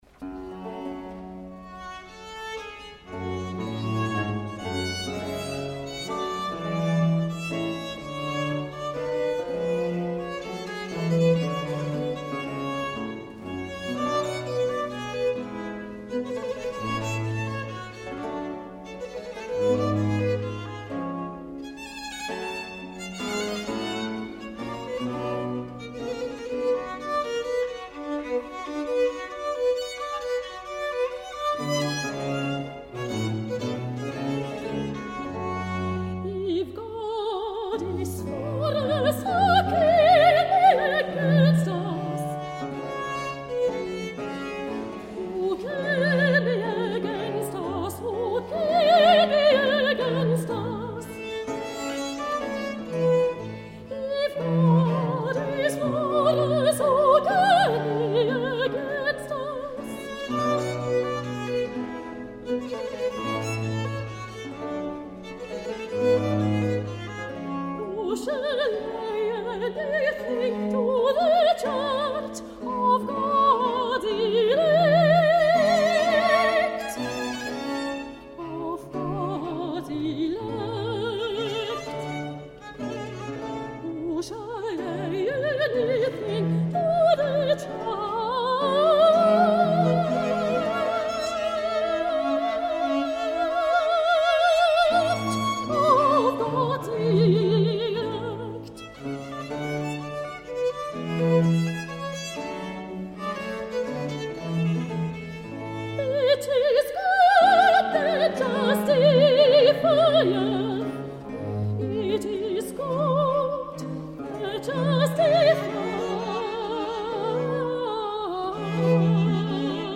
01-If-God-be-for-us-Arie-aus_Messiah_Haendel.mp3